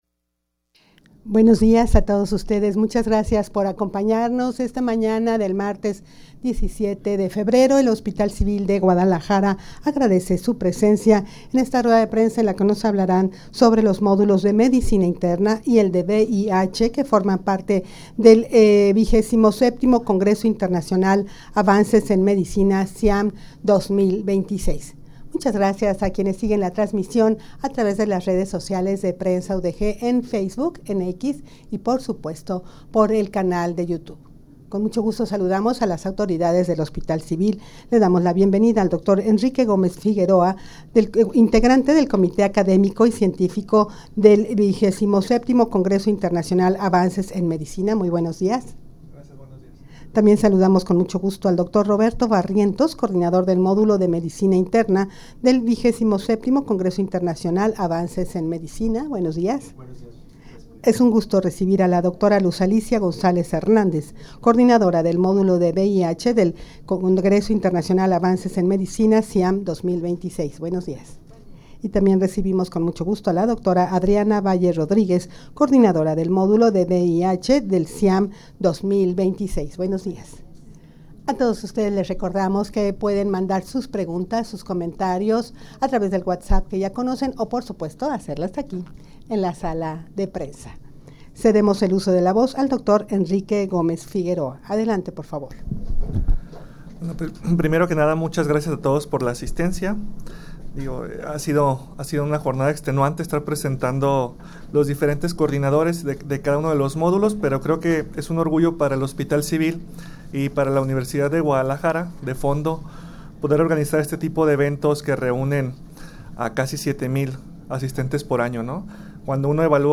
Audio de la Rueda de Prensa
rueda-de-prensa-modulos-de-medicina-interna-y-el-de-vih-que-forman-parte-del-xxvii-ciam-2026.mp3